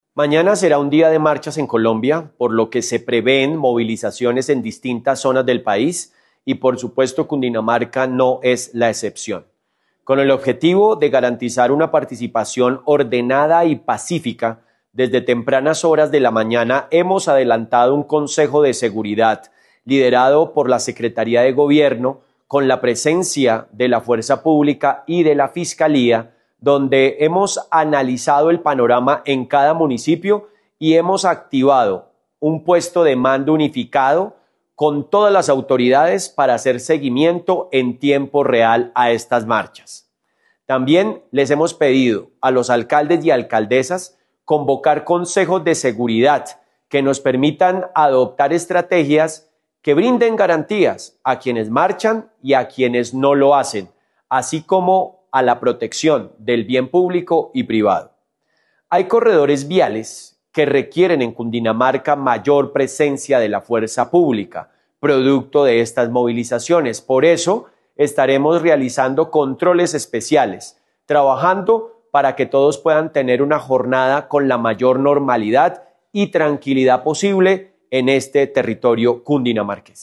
MAYO_28_GOBERNADOR.mp3